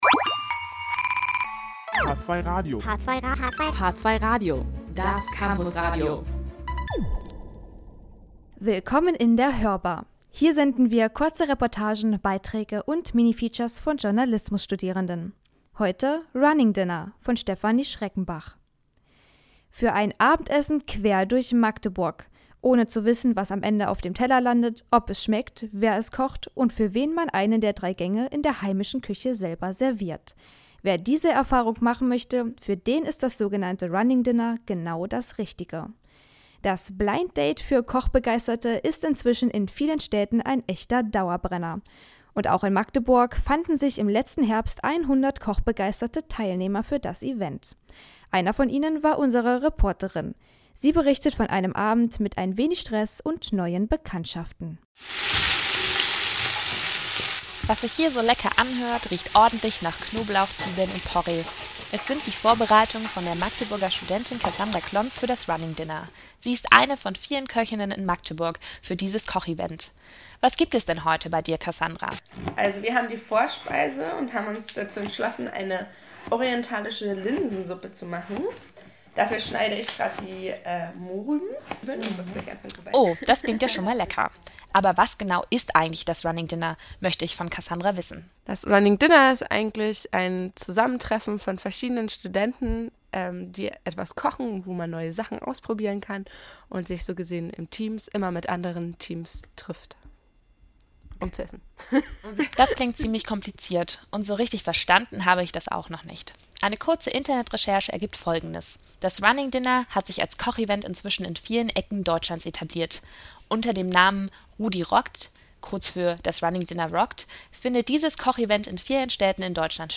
Und auch in Magdeburg fanden sich im letzten Herbst 100 kochbegeisterte Teilnehmer für das Event. Eine von ihnen war unsere Reporterin. Sie berichtet von einem Abend mit ein wenig Stress und neuen Bekanntschaften. https